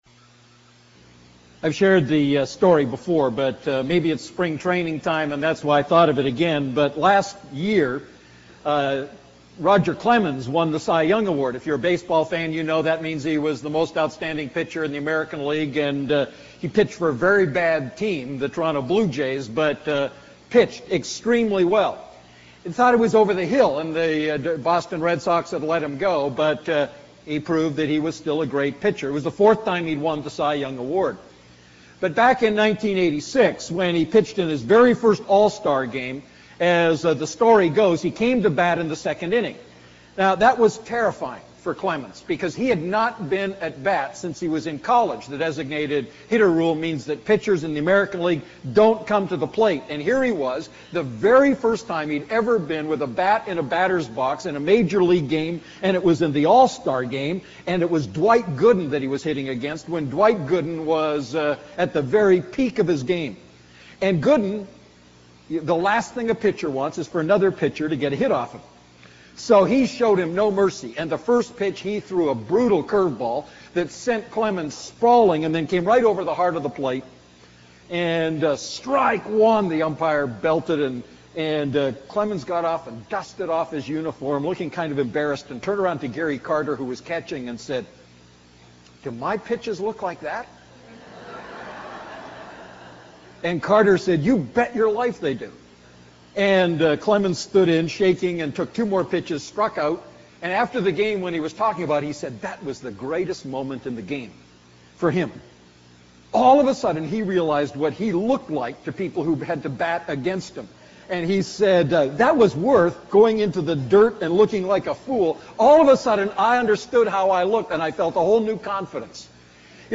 A message from the series "I John Series."